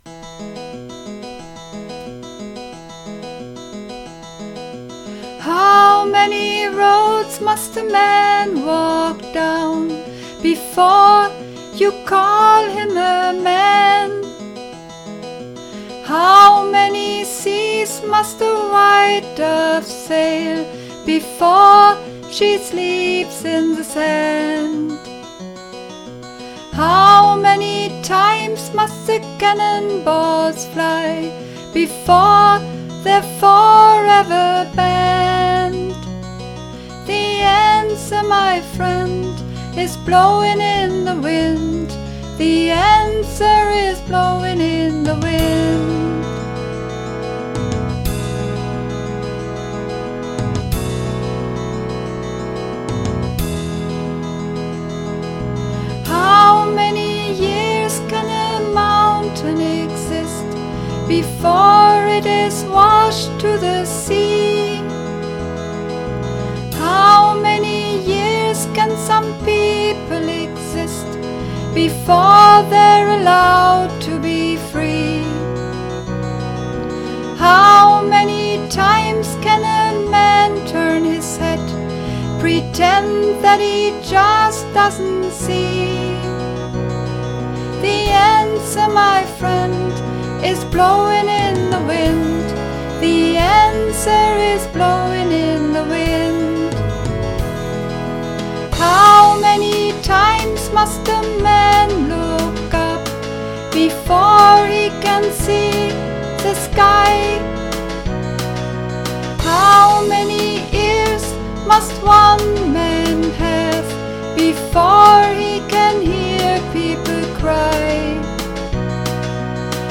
Übungsaufnahmen
Blowin' In The Wind (Sopran)
Blowin_In_The_Wind__3_Sopran.mp3